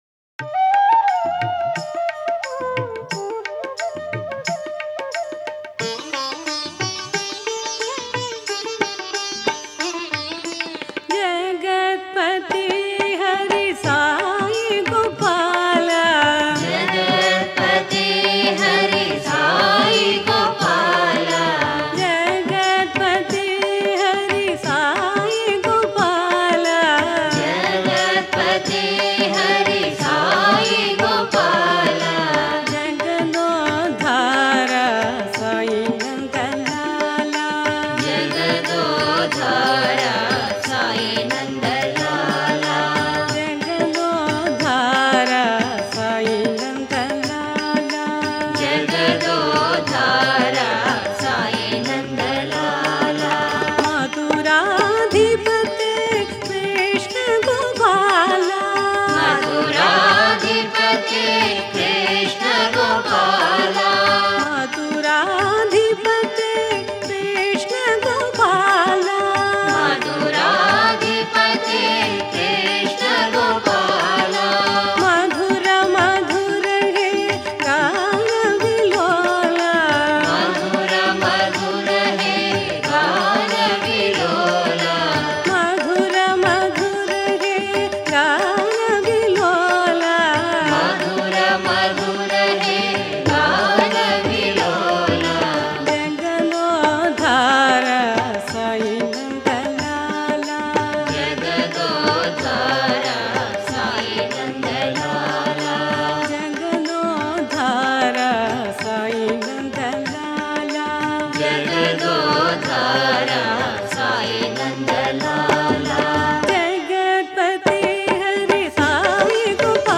Author adminPosted on Categories Krishna Bhajans